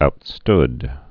(out-std)